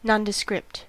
Ääntäminen
IPA : /ˈnɒndɪskɹɪpt/
IPA : /nɑndəsˈkrɪpt/